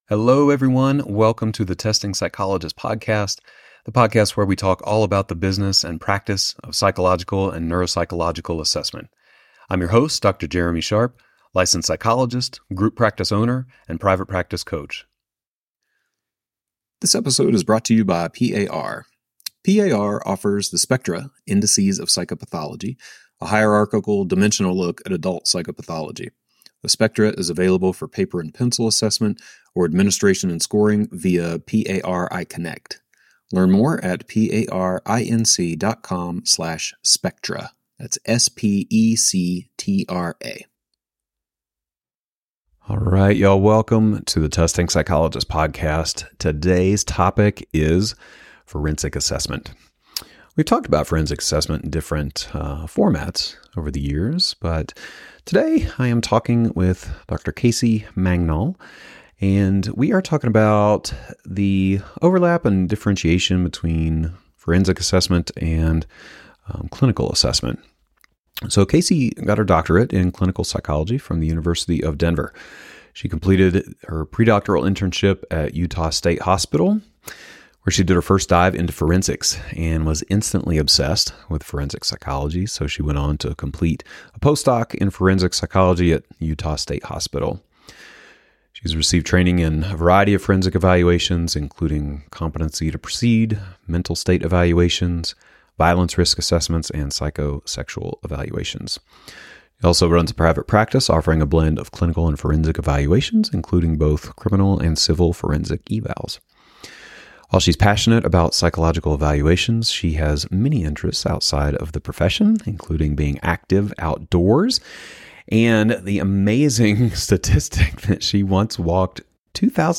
In the News
interview